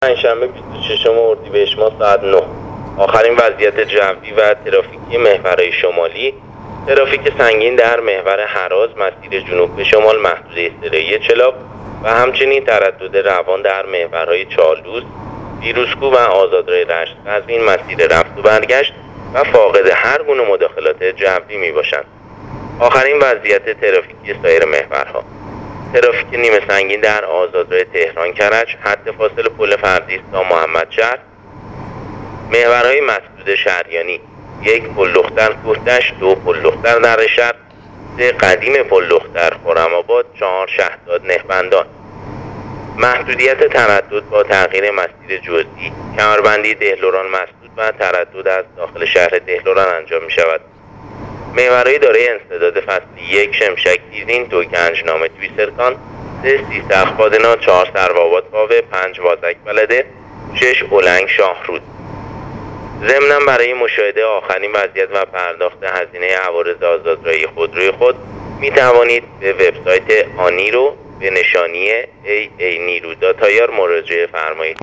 گزارش رادیو اینترنتی وزارت راه و شهرسازی از آخرین وضعیت‌ ترافیکی راه‌های کشور تا ساعت ۹ پنج شنبه ۲۶ اردیبهشت/ترافیک سنگین در محور هراز مسیر جنوب به شمال/تردد روان در محورهای چالوس، فیروزکوه و آزادراه رشت-قزوین